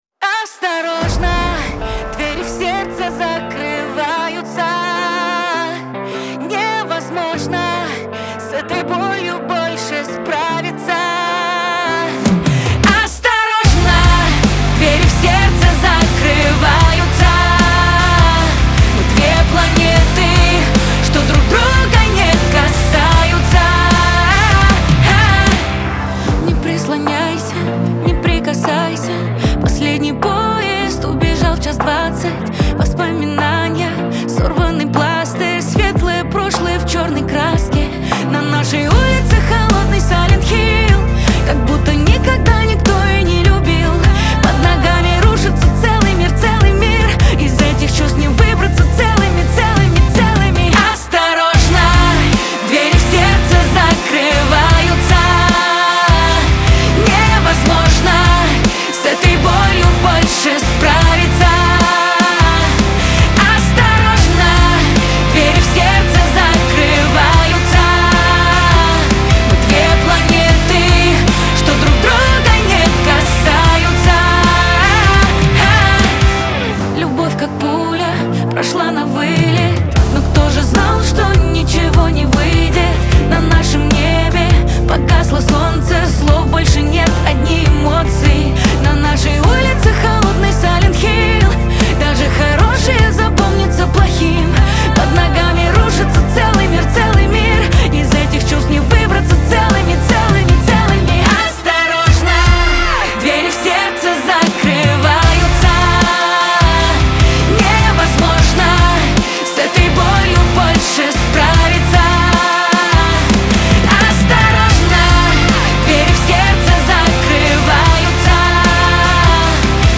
Лирика , pop
эстрада